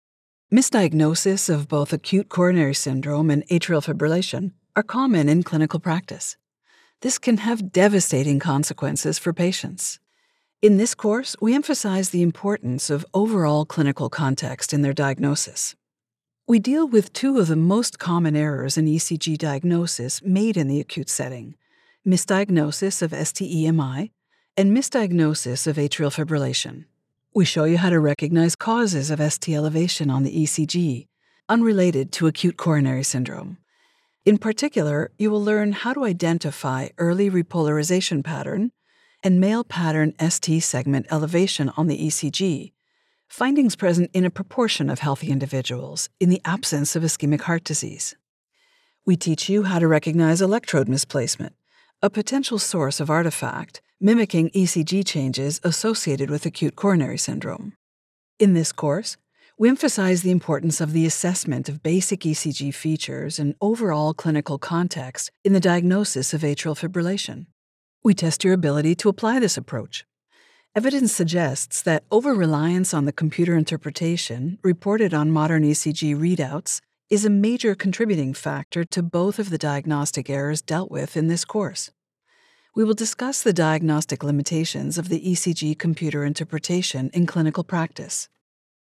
Montreal English / Canadian English
Velvety smooth, luxurious contralto.
Paired with a delivery that is professional and authoritative, the Doctor’s voice is sophisticated, clear, smooth and articulate.